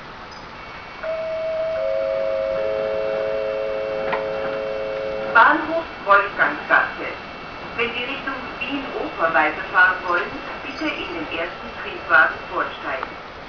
Haltestellenansagen